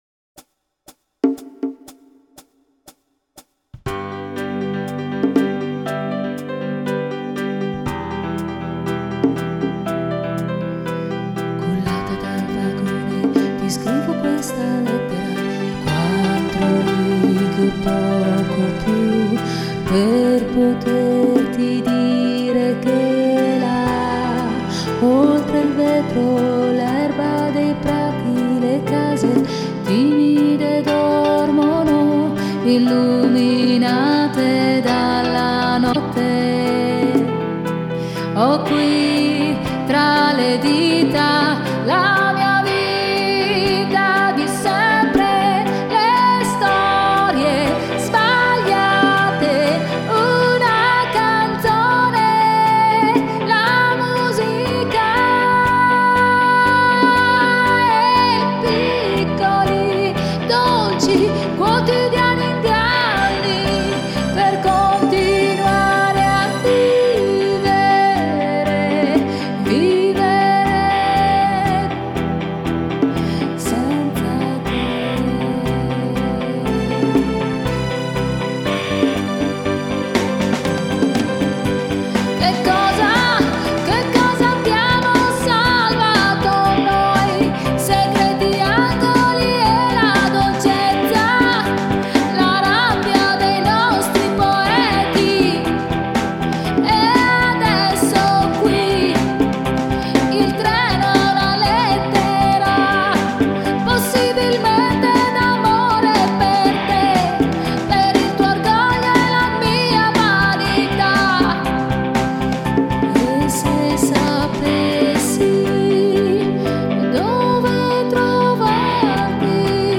Si esibisce come cantante solista in situazioni live dal 1994.